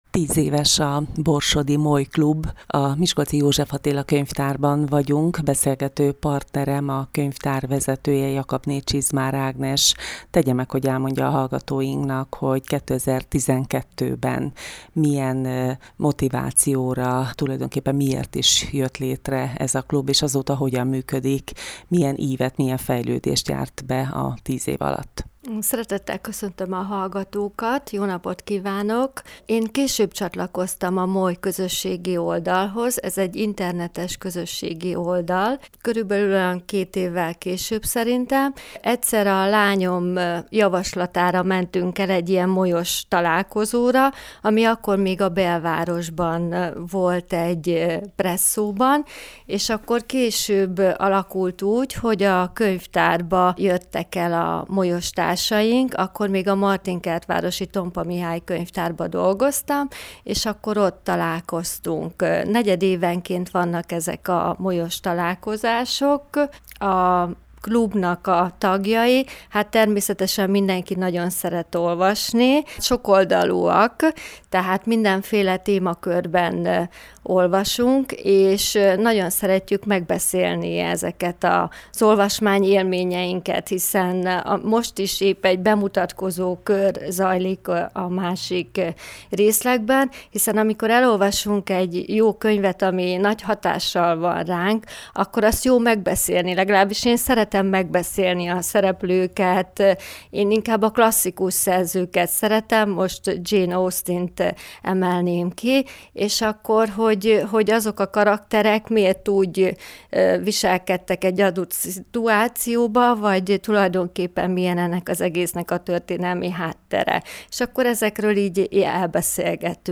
A társaság 10 éves fennállását ünnepelte a napokban. A születésnapi összejövetelnek a miskolci József Attila Könyvtár adott otthont.